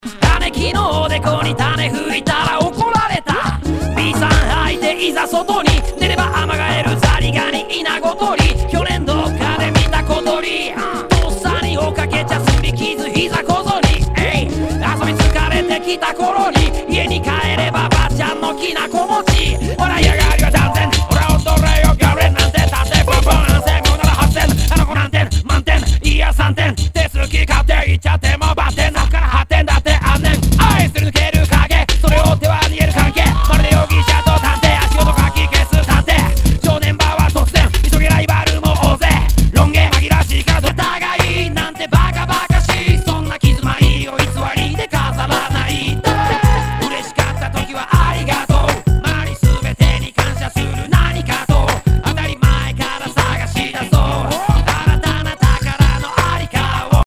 和モノ/JAPANEASE GROOVE
ナイス！Jレゲエ！
[VG ] 平均的中古盤。スレ、キズ少々あり（ストレスに感じない程度のノイズが入ることも有り）